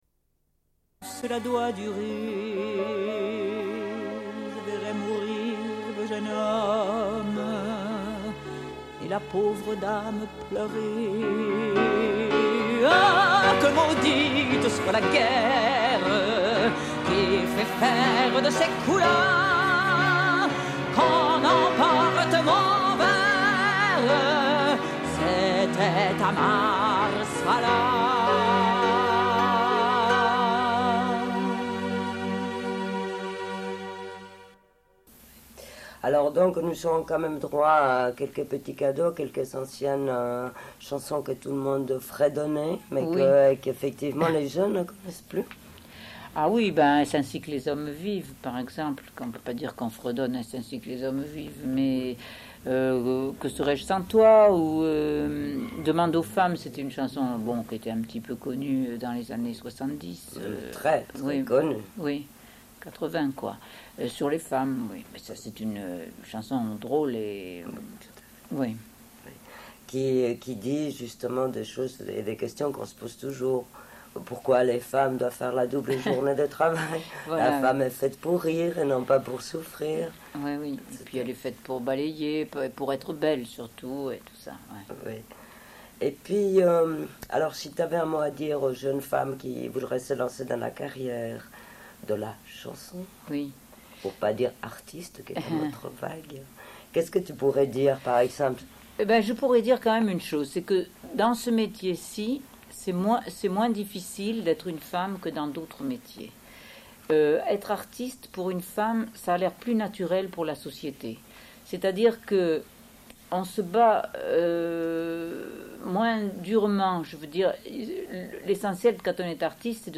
Une cassette audio, face B31:07
Suite de l'émission Une musicienne, une musique consacrée à Francesca Solleville. Diffusion d'un entretien avec l'artiste.